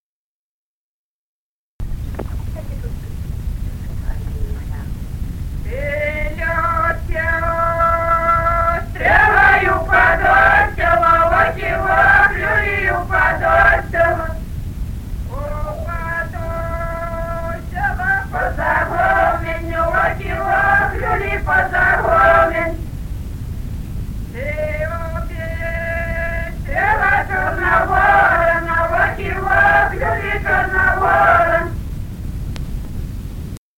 Народные песни Стародубского района «Ты лети, стрела», юрьевская таночная.
с. Курковичи.